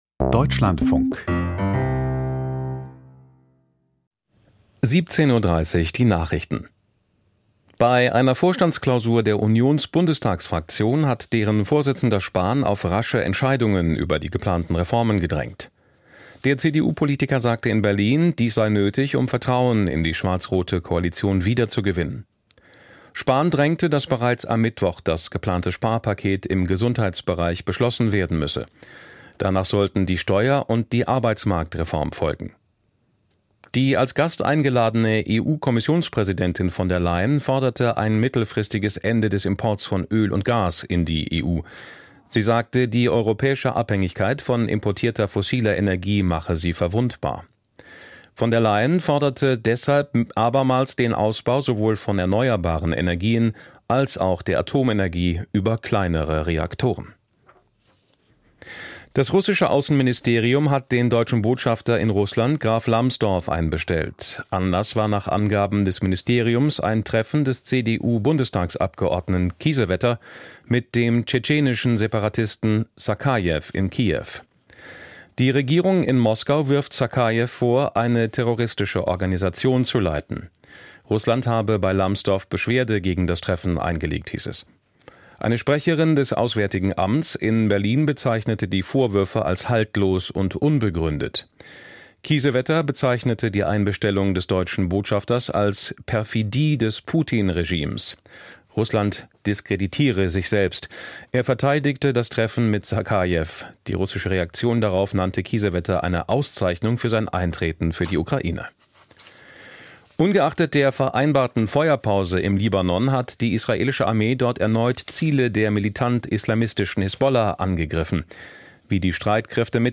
Die Nachrichten vom 27.04.2026, 17:30 Uhr